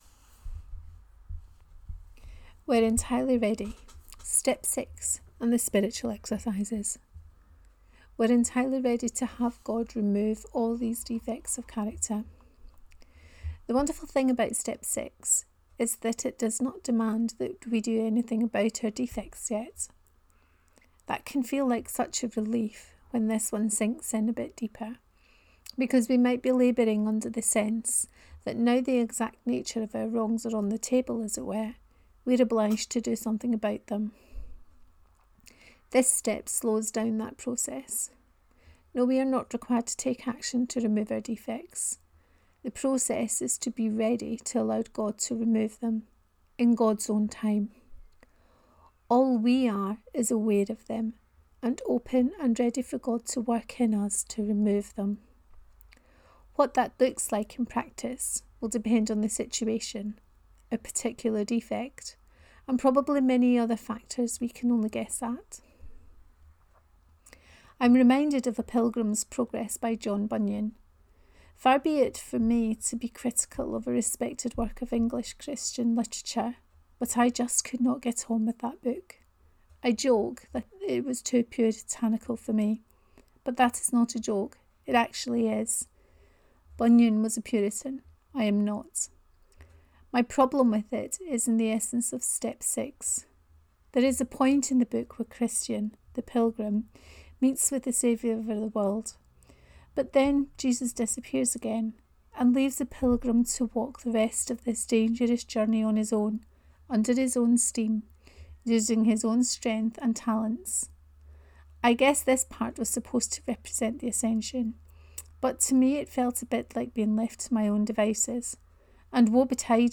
Were entirely ready…1: Reading of this post